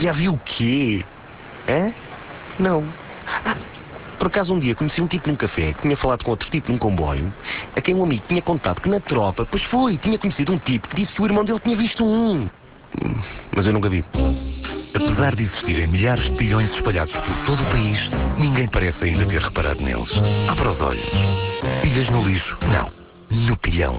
clique para ouvir) passa na RFM e TSF e tem 2 spots diferentes que até ao dia 16 de Dezembro tiveram 197 inserções realizando um investimento a preço tabela de 50 666 euros.